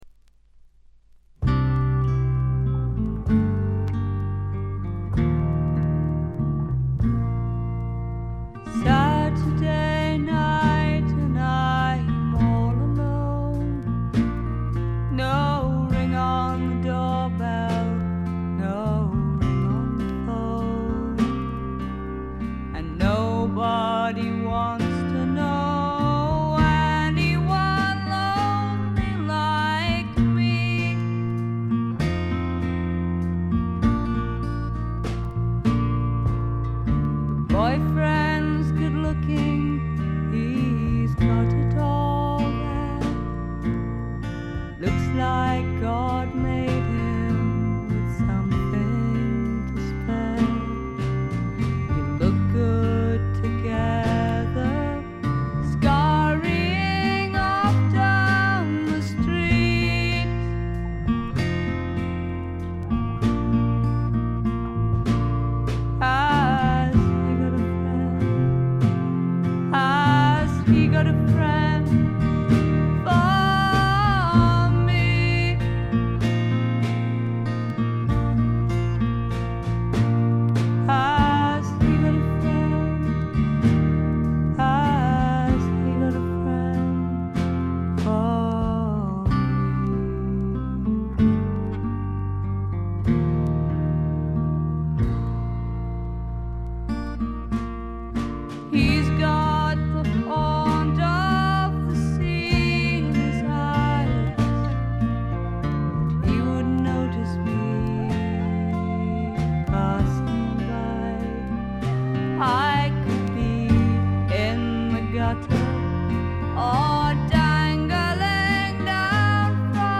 部分試聴ですが、ほとんどノイズ感無し。
英国のフォークロックはこう来なくっちゃというお手本のようなもの。
試聴曲は現品からの取り込み音源です。